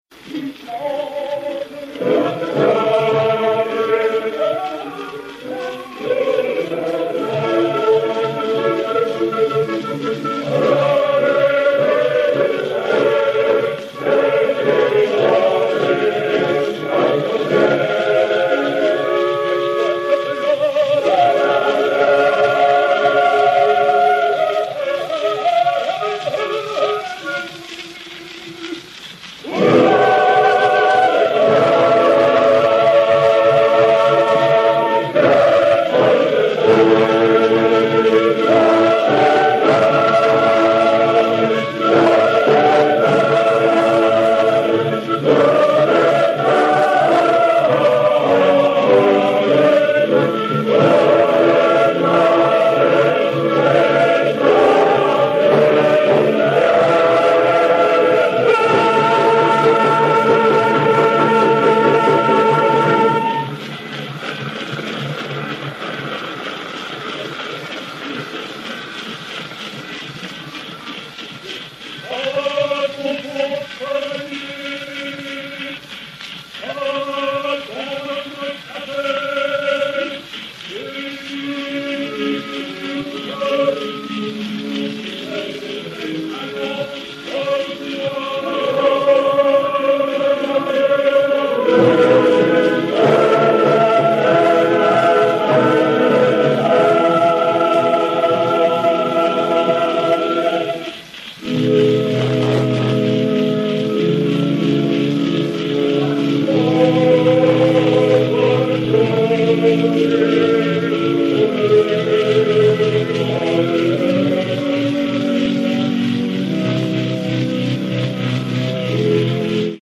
Albert Alvarez (Rodrigue) et Orch. du Metropolitan Opera de New York enr. dans ce théâtre le 19 février 1902 sur un cylindre par Mapleson